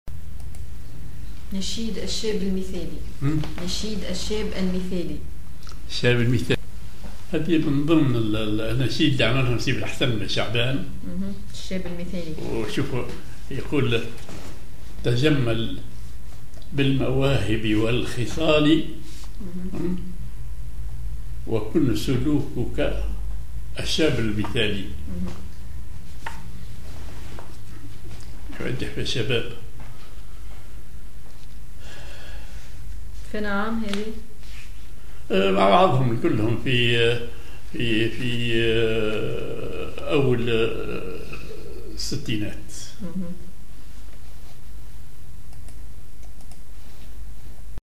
Maqam ar لامي
genre نشيد